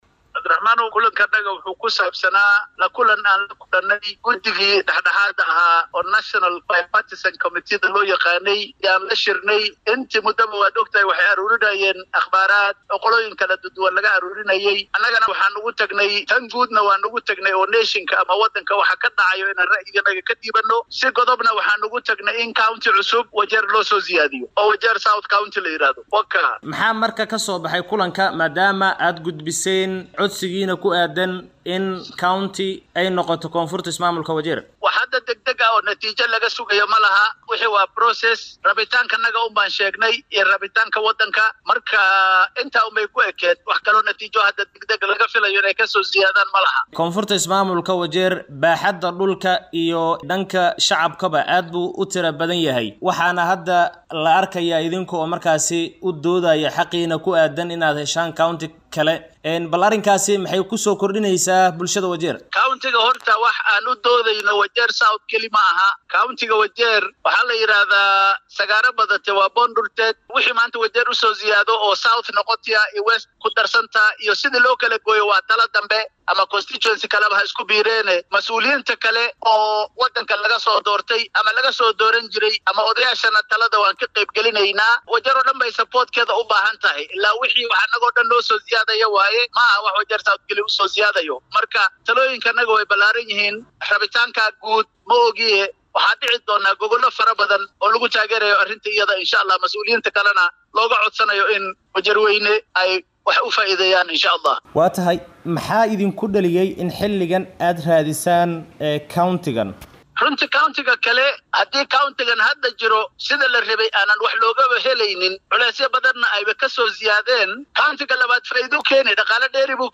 ayaa khadka taleefoonka ugu warramay